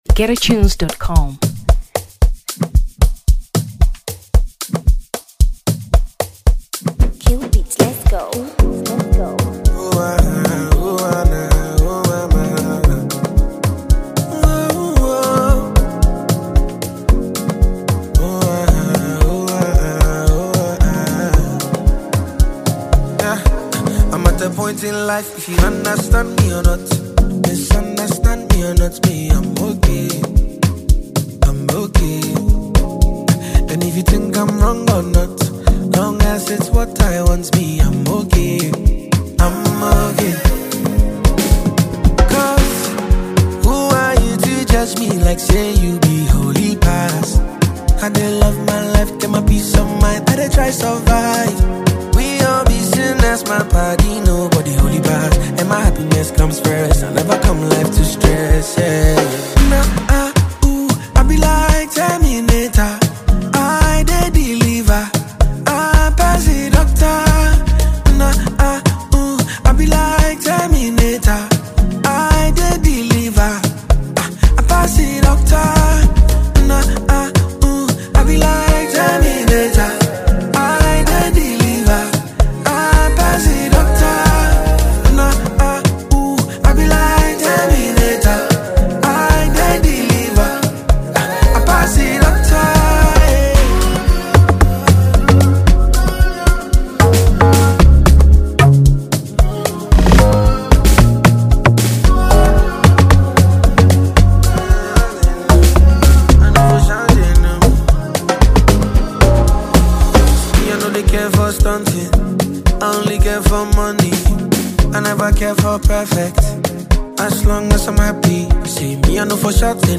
Afrobeats 2023 Ghana